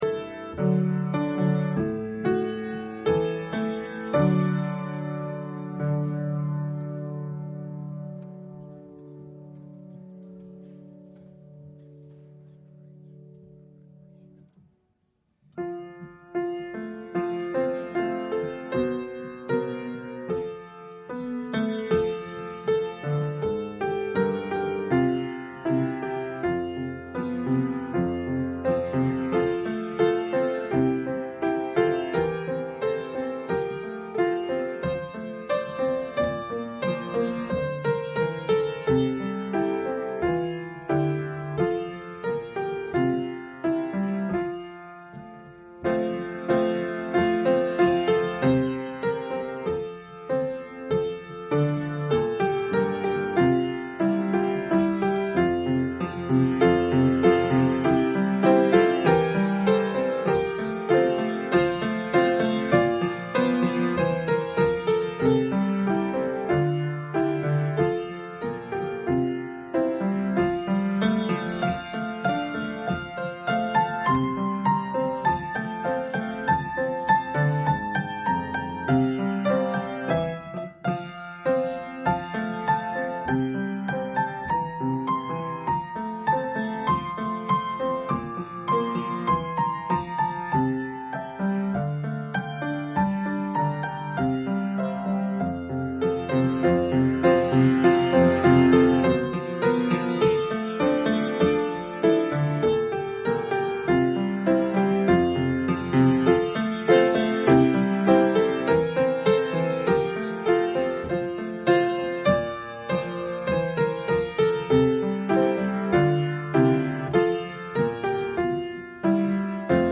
Exodus 2:11-22 Service Type: Sunday Afternoon Bible Text